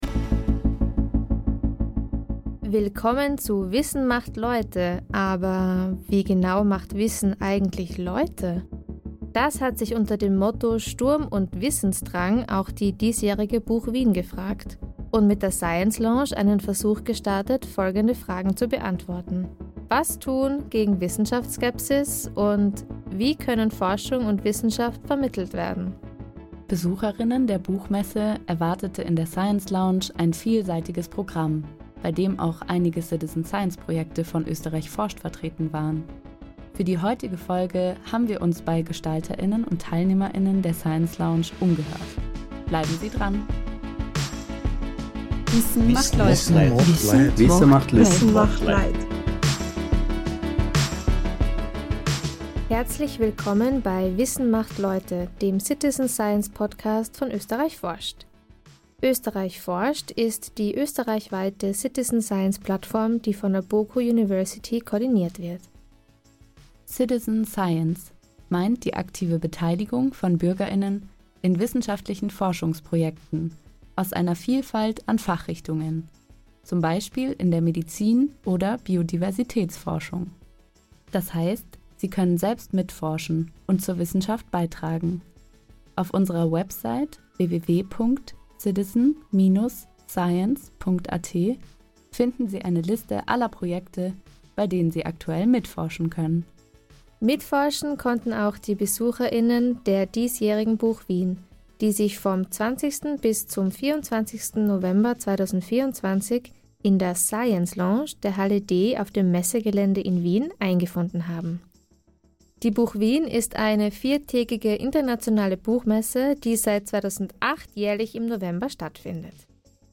Dort waren neben vielen Vorträgen auch einige Citizen Science Projekte von Österreich forscht mit Mitmachstationen, Ausstellungen und Workshops vertreten. Für diese Ausgabe von „Wissen macht Leute“ waren wir vor Ort und haben mit Gestalter*innen und Teilnehmer*innen der Science Lounge gesprochen.